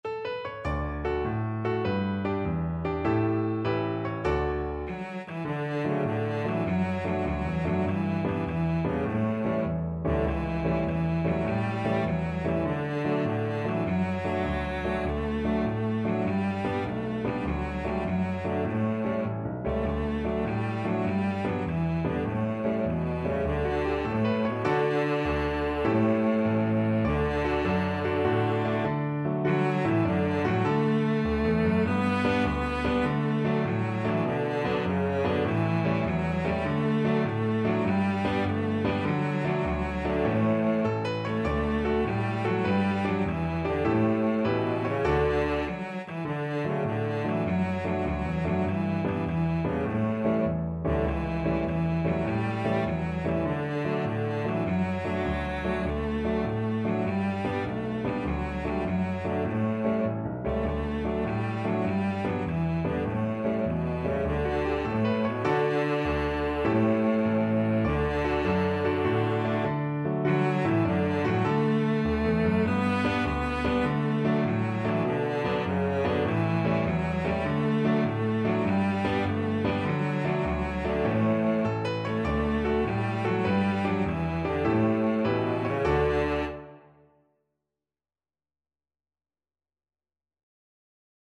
Cello
D major (Sounding Pitch) (View more D major Music for Cello )
Allegro .=c.100 (View more music marked Allegro)
6/8 (View more 6/8 Music)
Traditional (View more Traditional Cello Music)